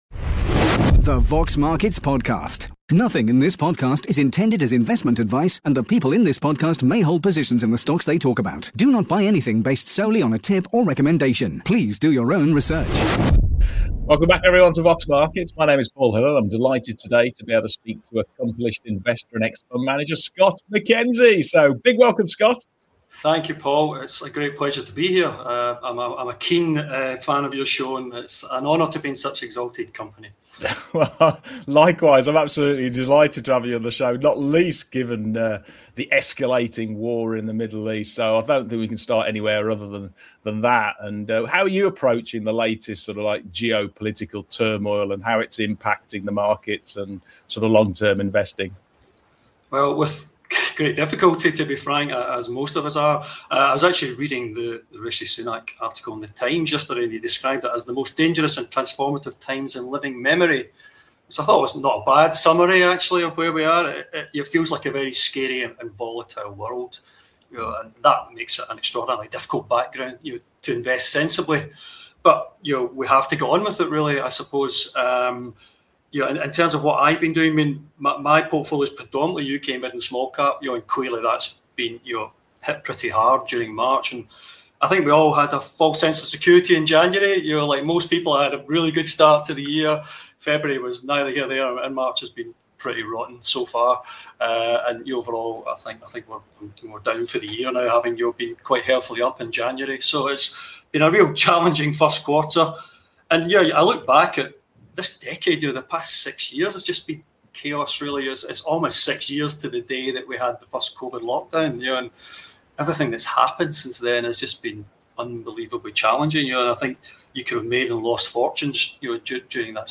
Watch on YouTubeIn this fascinating interview